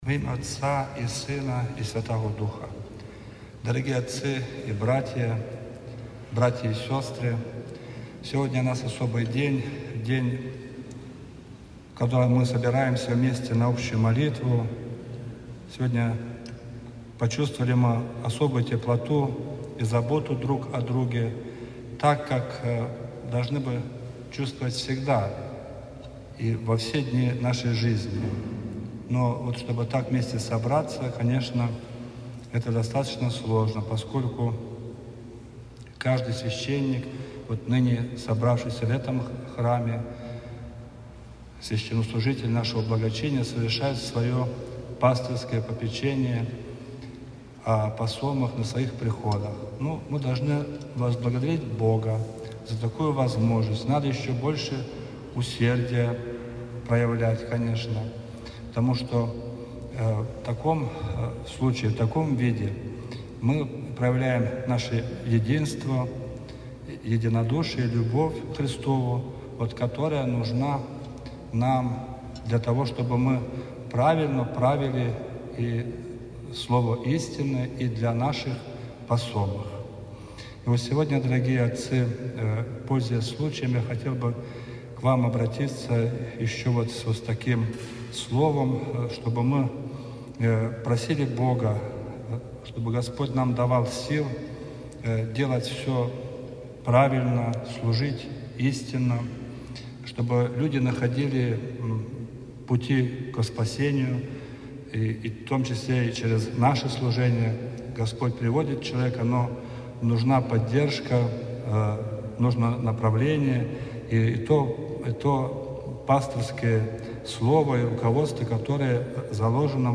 26 марта 2025 года, в среду 4-й седмицы Великого поста, Крестопоклонной, в строящемся храме Всемилостивого Спаса была совершена соборная молитва духовенства Красносельского благочиния.
Песнопения богослужения исполнил хор духовенства благочиния